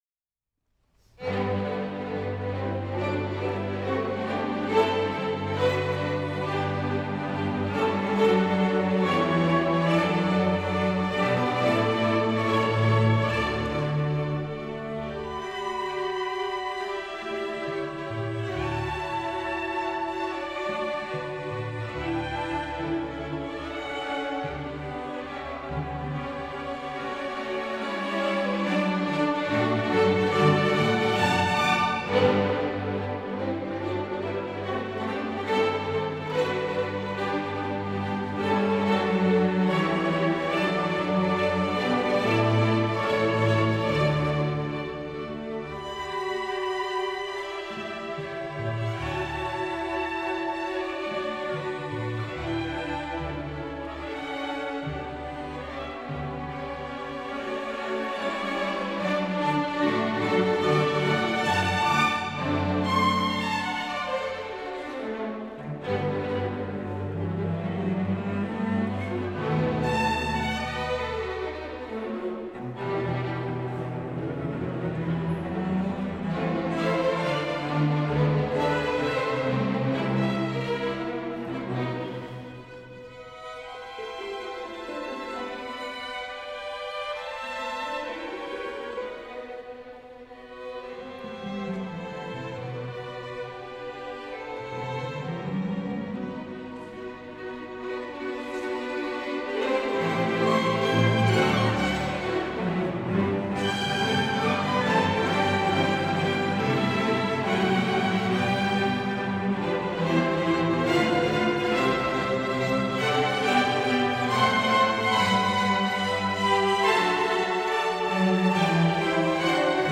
Beispielaufnahmen | KAMMERORCHESTER BERLINER CAPPELLA e.V.
Konzert vom 13.06.2015 in der Matthäuskirche